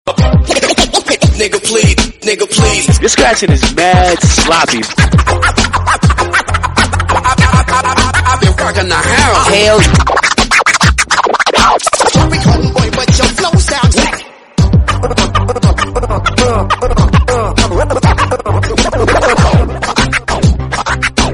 Dj Scratching Remix